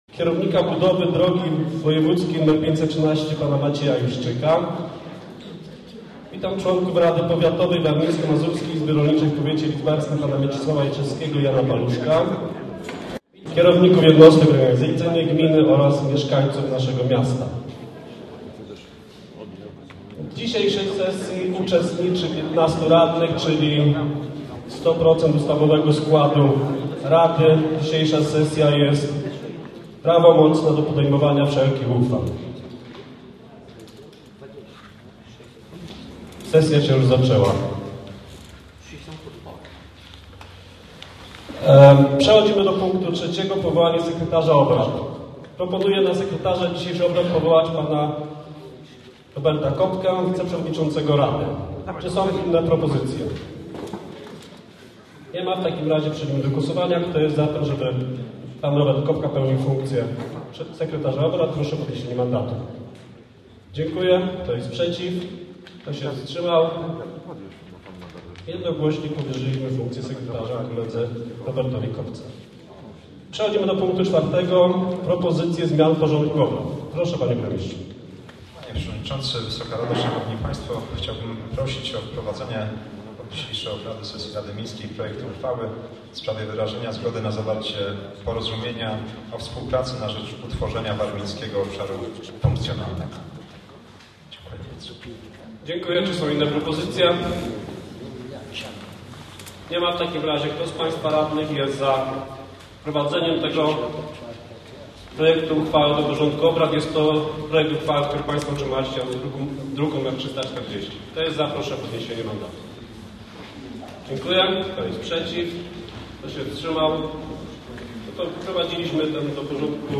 Sesja Rady Miasta i Gminy w Ornecie – relacja subiektywna.
Sesja trwała blisko 2 godziny i uczestniczyło w niej dokładnie 71 osób.
Sala plenarna w orneckim ratuszu znacznie okazalsza niż ta w Lidzbarku, wyposażona w profesjonalnie nagłośnienie, co wydaje się niezbędne przy tej wielkości pomieszczeniu.